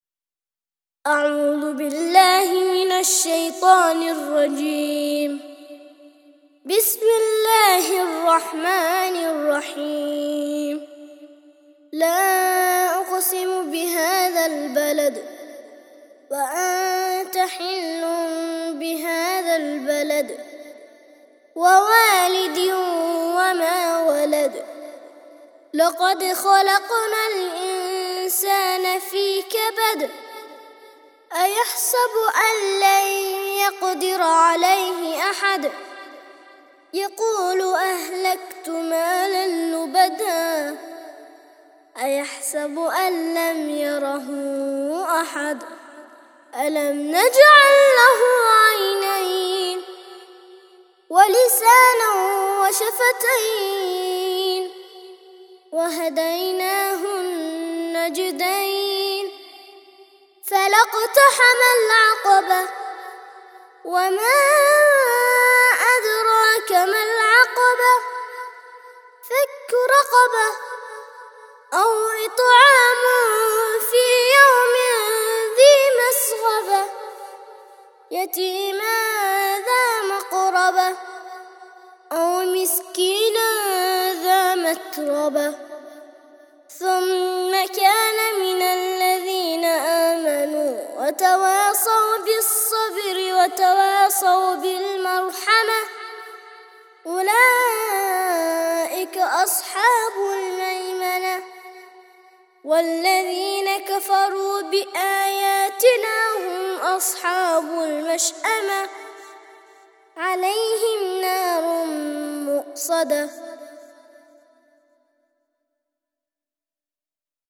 90- سورة البلد - ترتيل سورة البلد للأطفال لحفظ الملف في مجلد خاص اضغط بالزر الأيمن هنا ثم اختر (حفظ الهدف باسم - Save Target As) واختر المكان المناسب